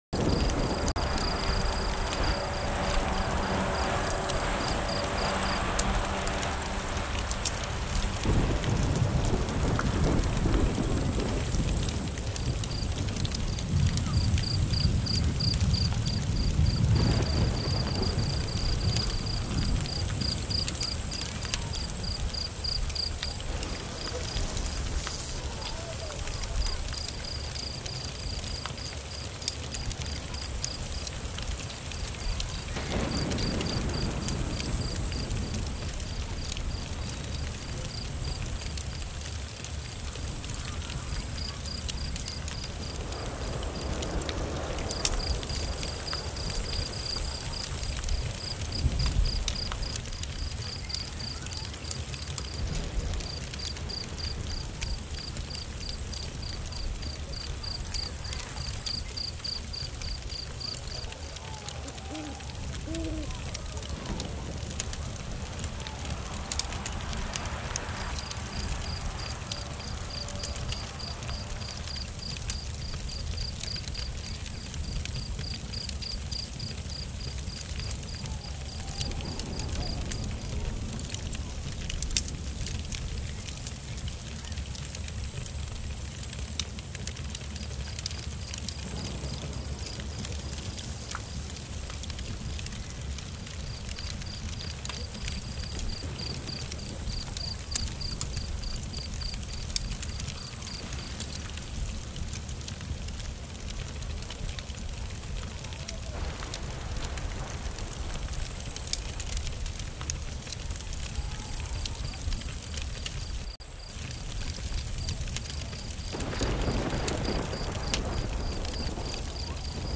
2:56 · Soft Piano